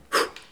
Les sons ont été découpés en morceaux exploitables. 2017-04-10 17:58:57 +02:00 90 KiB Raw History
pfiou_01.wav